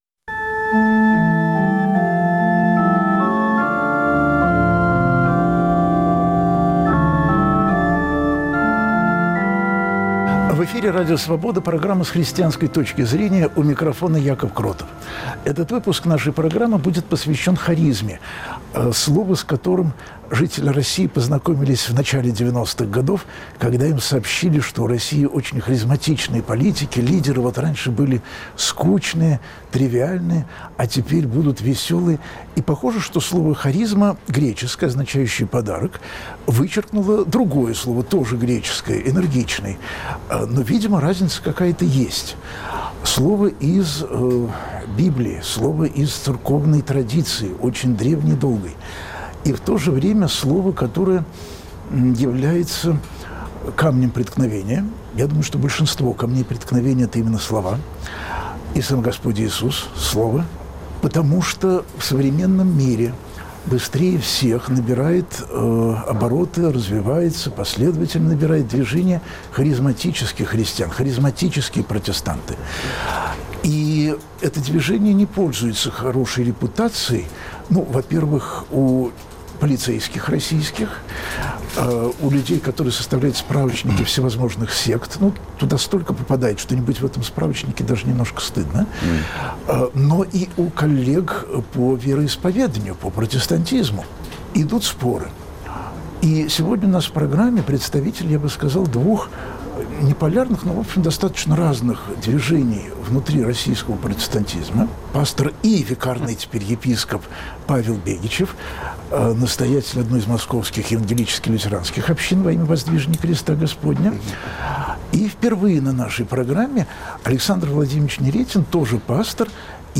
В программе, которую ведет священник Яков Кротов, слушают друг друга верующие и неверующие, чтобы христиане в России были не только большинством, но и работниками свободы Божьей и человеческой.